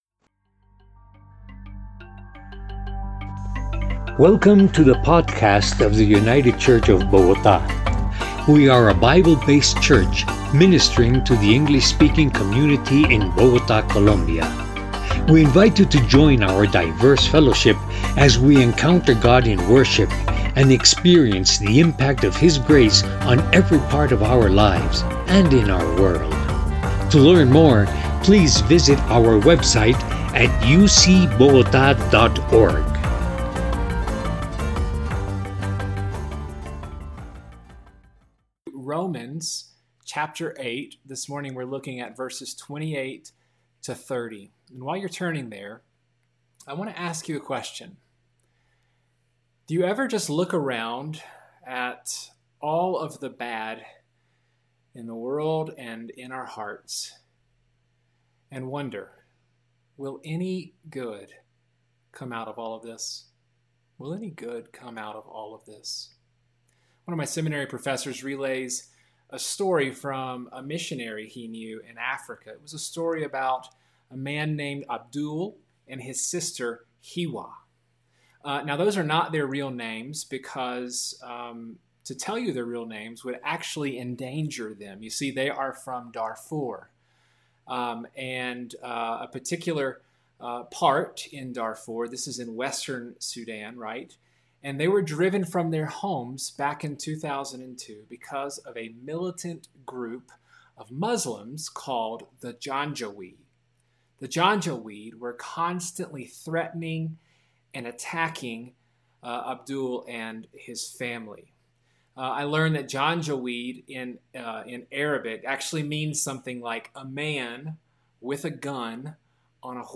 The Golden Chain – United Church of Bogotá